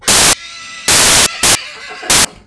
bonne voix....
henissement_poulinou.wav